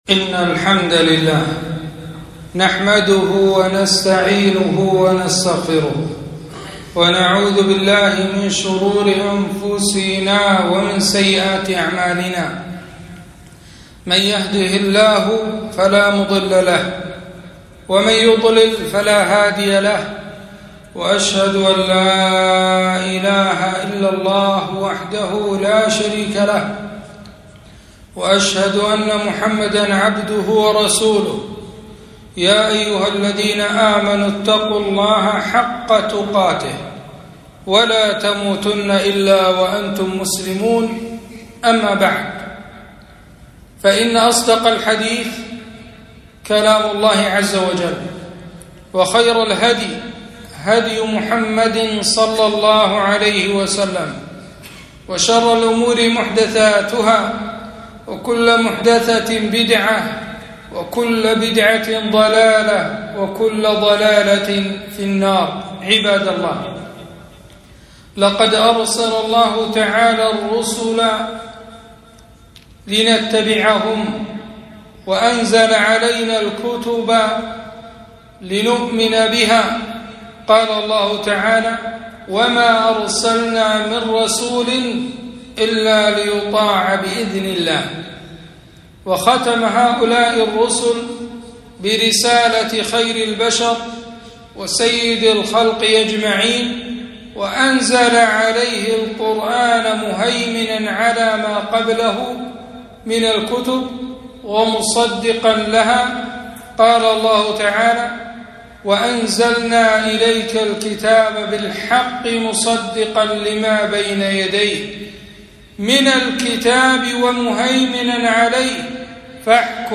خطبة - وجوب التمسك بالسنة النبوية